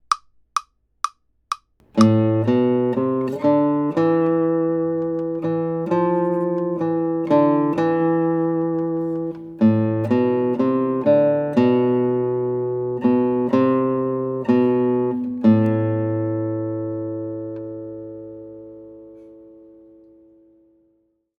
Pluck the melody with the thumb of the right-hand and work towards a goal tempo of allegro, about 138 BPM.
Pie in the Sky | Melody only